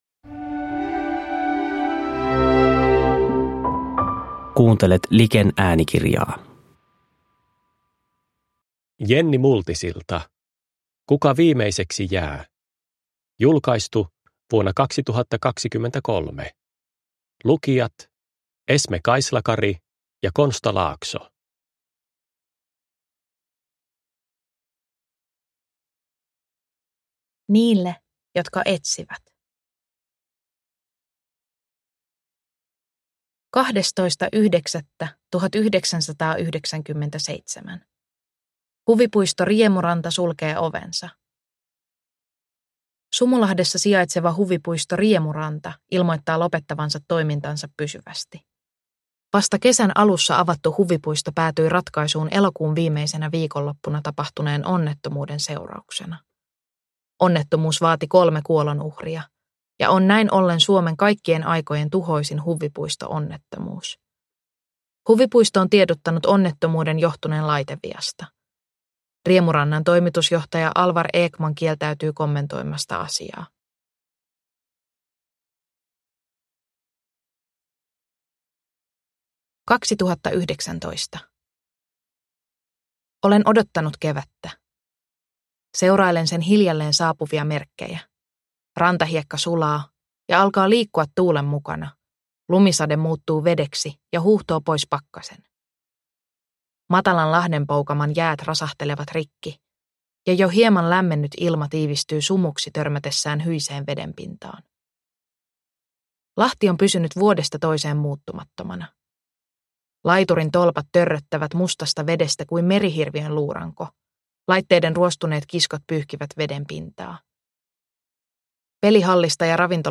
Kuka viimeiseksi jää – Ljudbok – Laddas ner